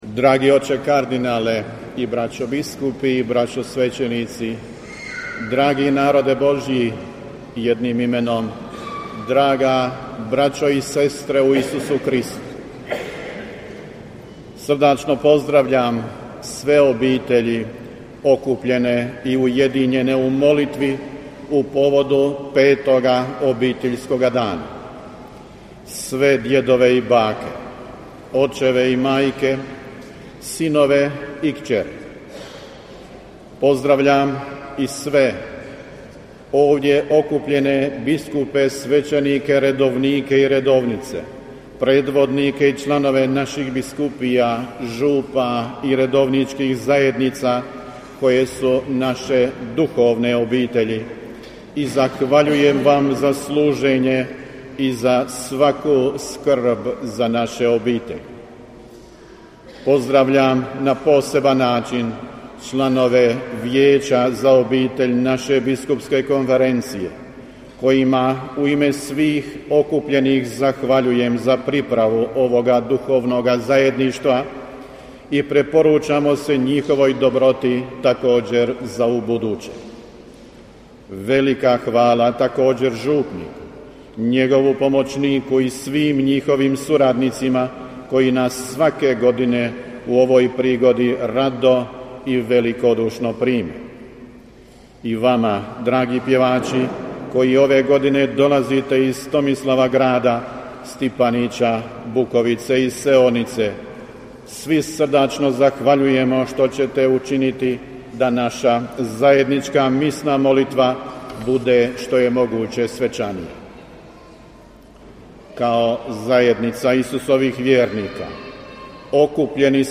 AUDIO: PROPOVIJED NADBISKUPA VUKŠIĆA TIJEKOM MISNOG SLAVLJA NA 5. OBITELJSKI DAN U BIH U KUPRESU - BANJOLUČKA BISKUPIJA